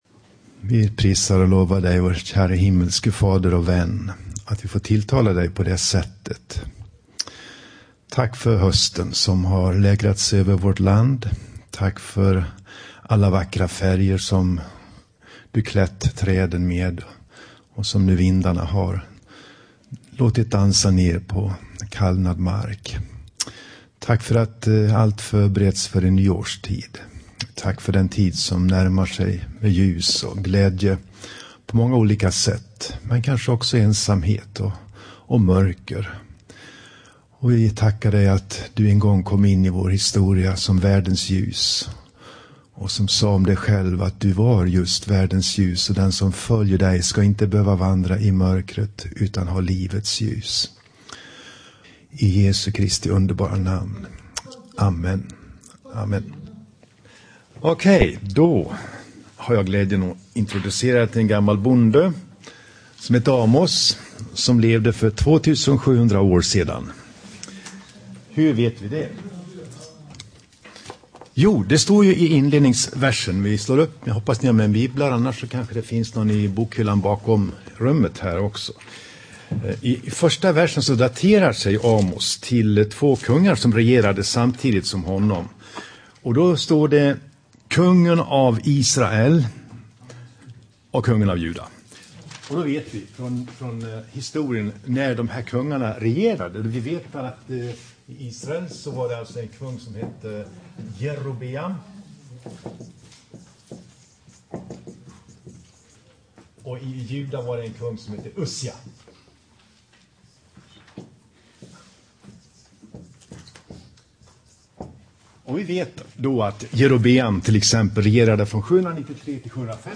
Inspelad i Tabernaklet i Göteborg 2012-11-21.